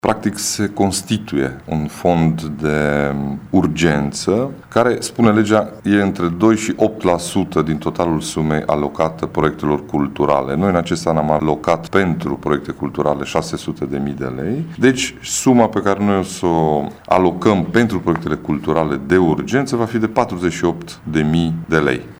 Președintele CJ Mureș, Ciprian Dobre a precizat că este vorba de aproape 50.000 de lei care vor fi folositi în acest scop: